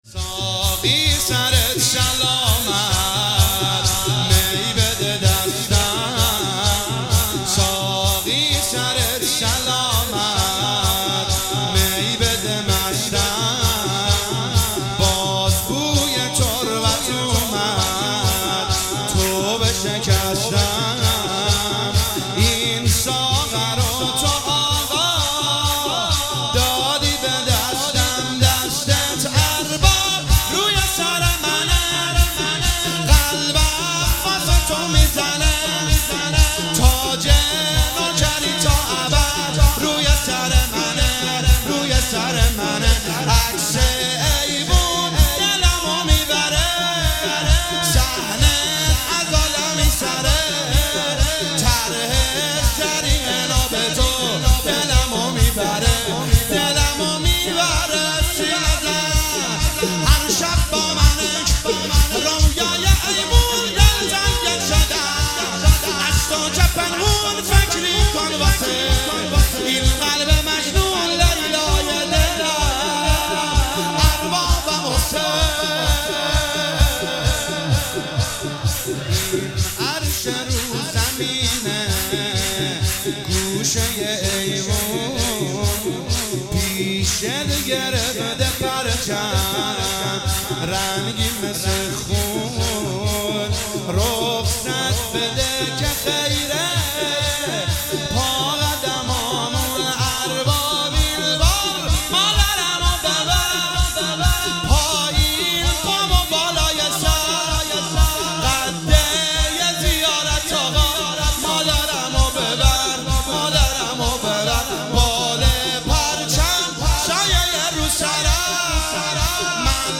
هیئت ام ابیها قم